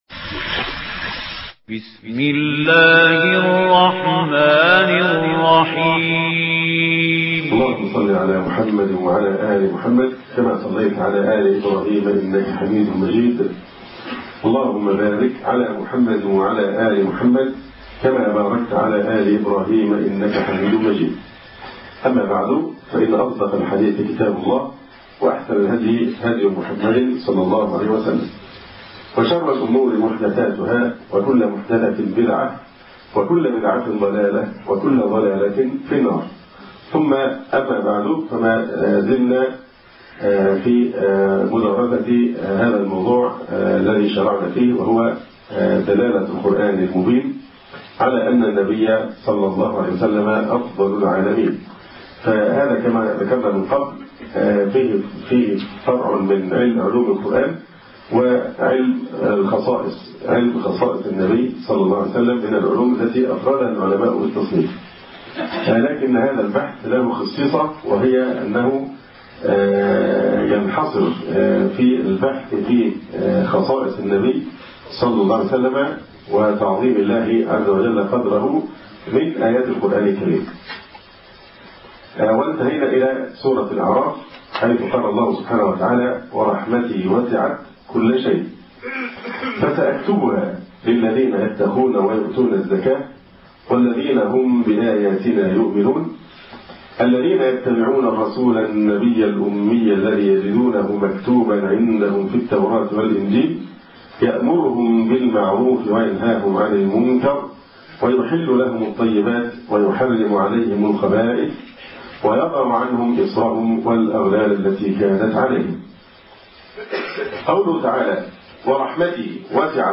المحاضرة الرابعة